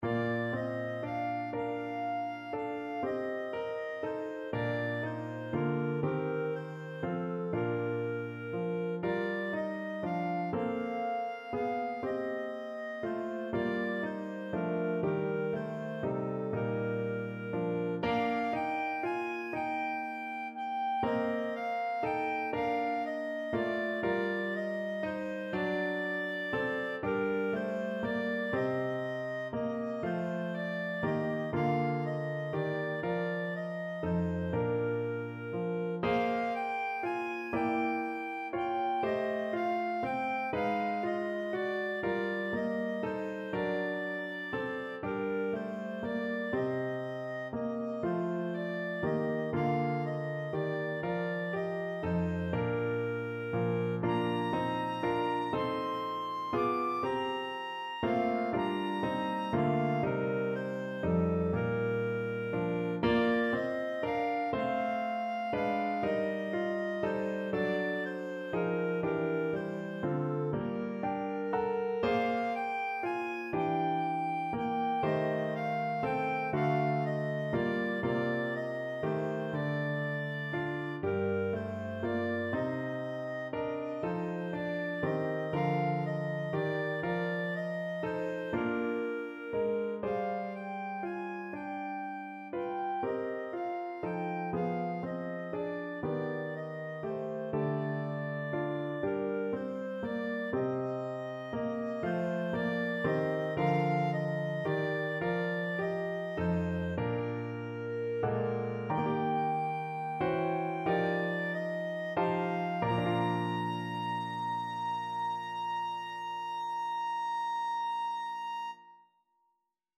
9/4 (View more 9/4 Music)
=120 Andante tranquillo
Classical (View more Classical Clarinet Music)